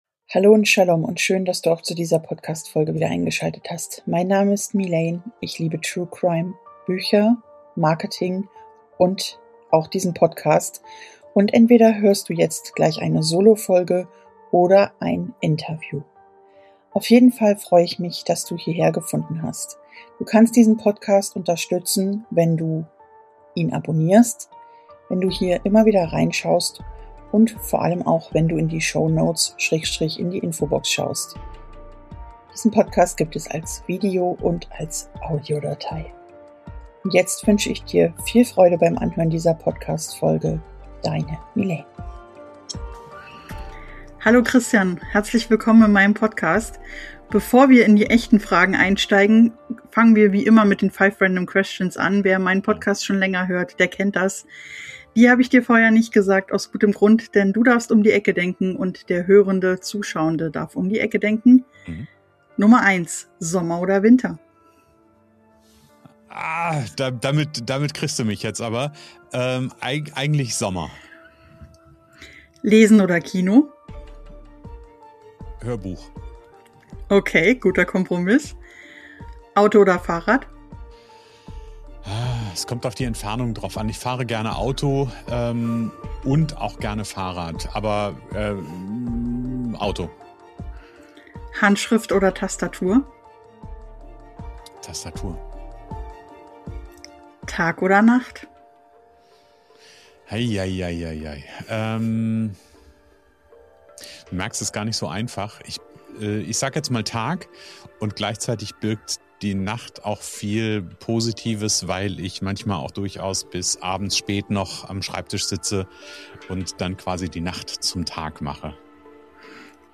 Im Interview: